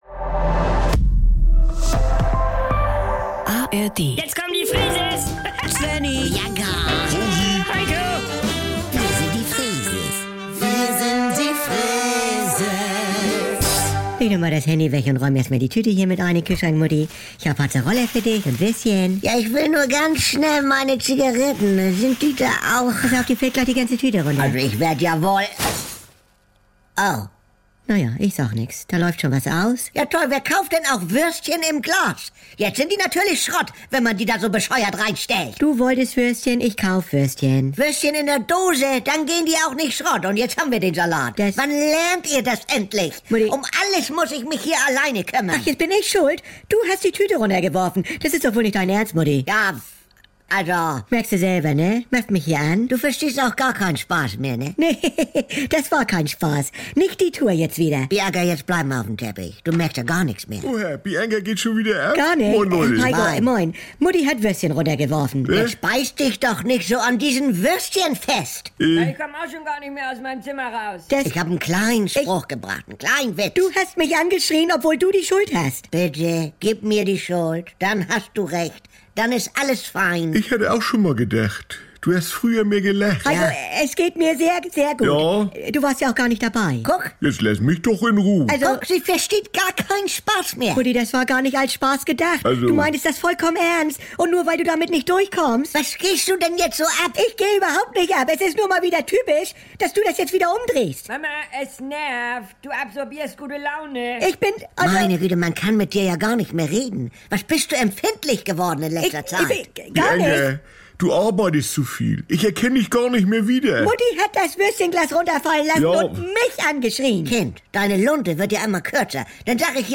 … continue reading 1871 epizódok # Saubere Komödien # NDR 2 # Komödie # Unterhaltung